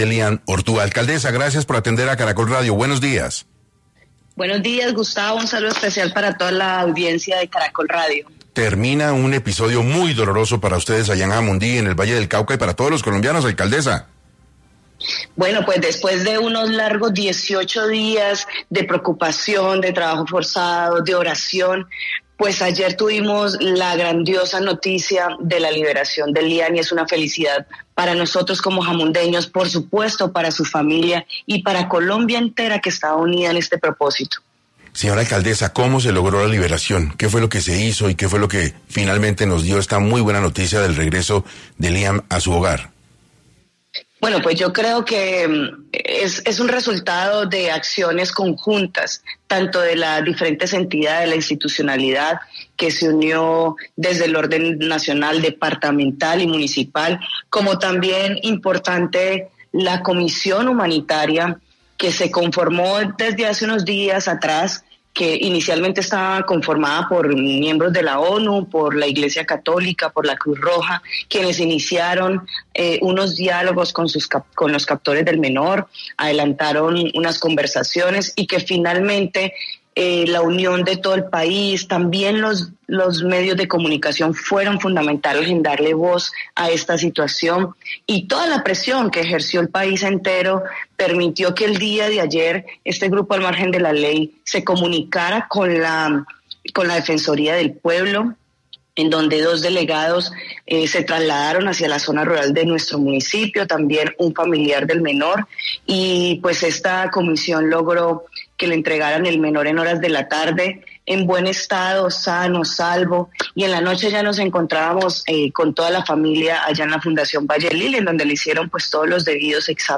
La alcaldesa de Jamundí, Paola Castillo, habló en 6AM sobre el estado de salud del menor y los pormenores de su liberación.